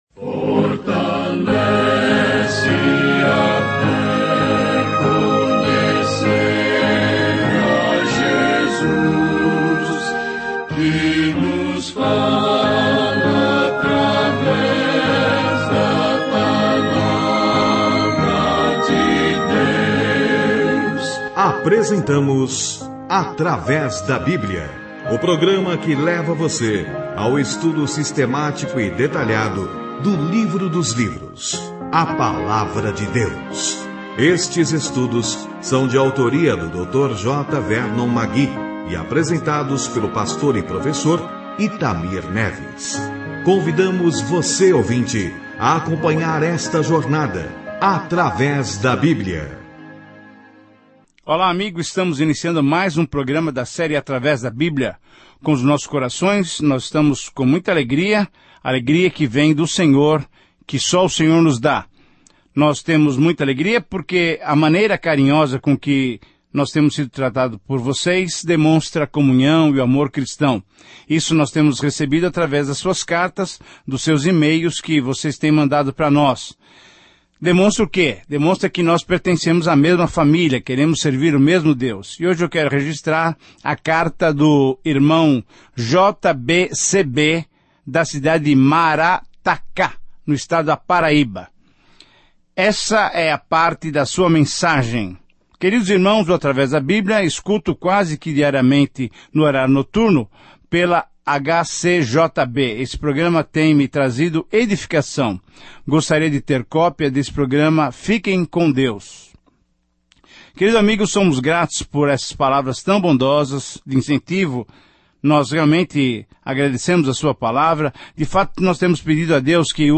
Viaje diariamente por Levítico enquanto ouve o estudo em áudio e lê versículos selecionados da palavra de Deus.